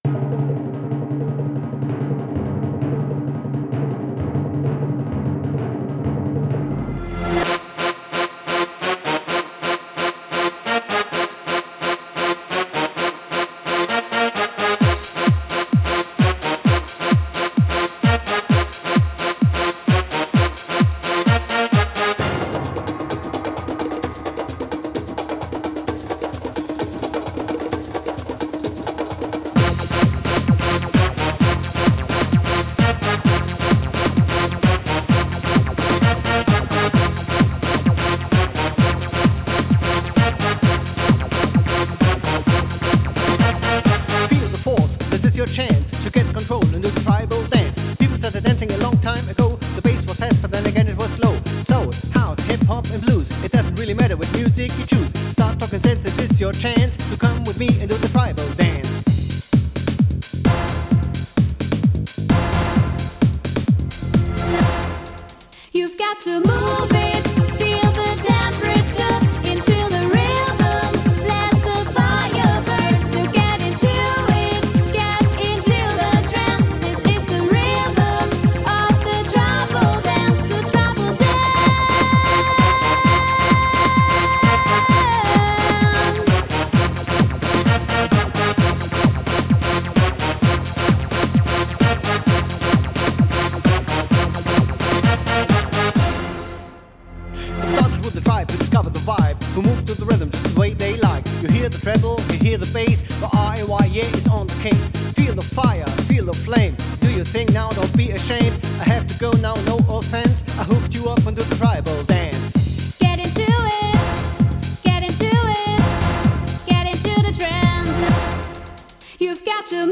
- Live gespielte Klassiker
Eurodance (90er)